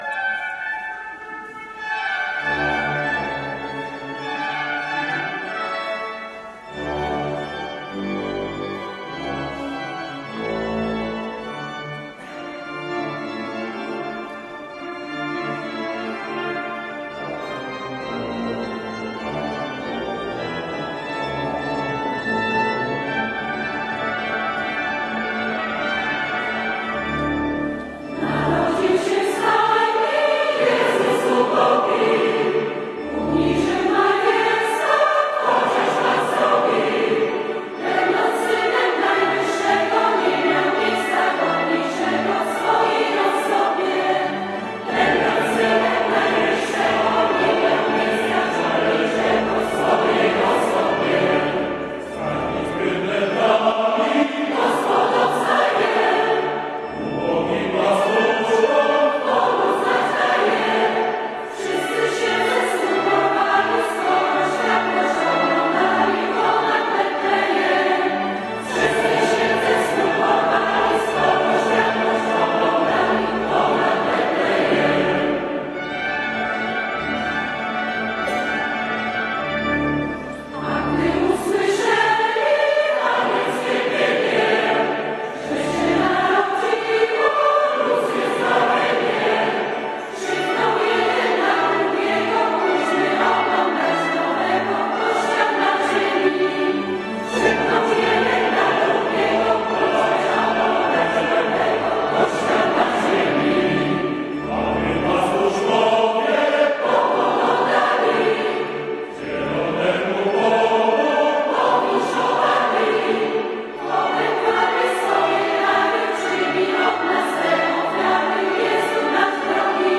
Do posłuchania – Lubelski Festiwal Chórów Parafialnych
Narodził się w stajni – wspólne wykonanie kolędy: